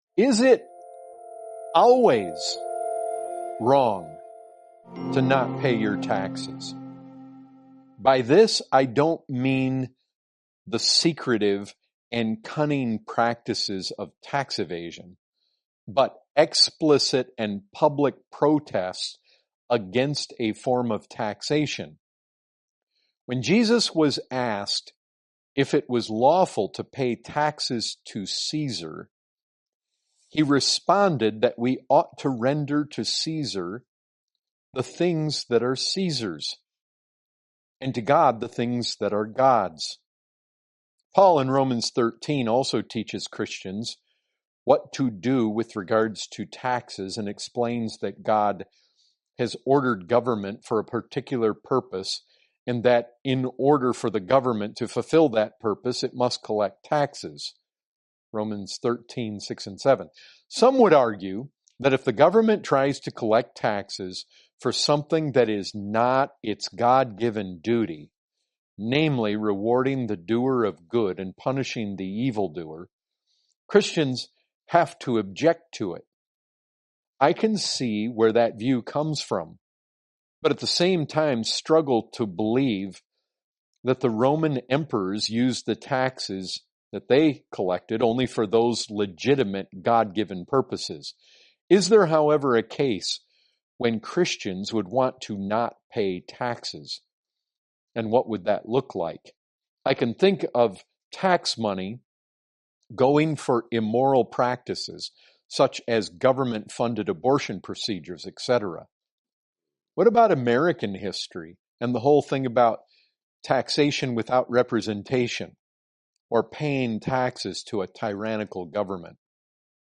2023 Category: Questions & Answers As a believer is it always wrong to not pay taxes?